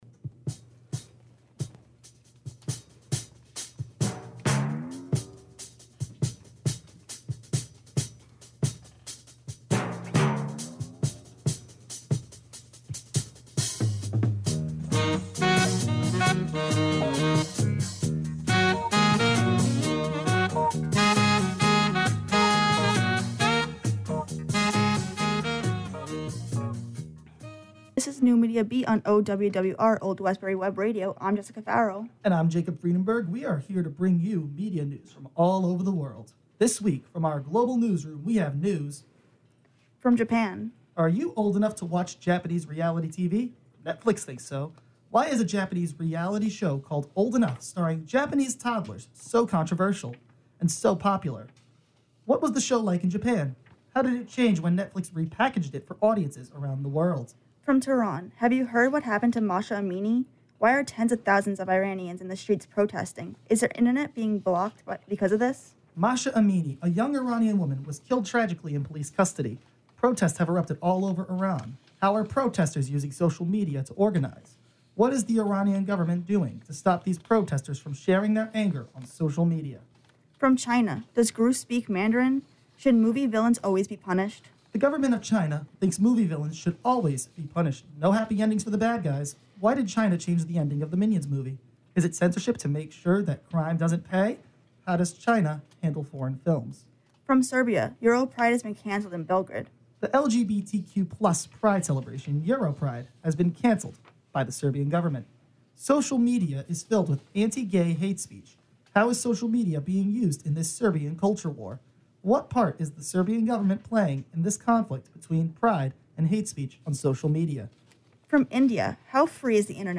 Russia: Censorship & War in Ukraine The NMB Podcast streams live on Old Westbury Web Radio every Thursday from 10:00-11:00 AM EST. Can’t listen live?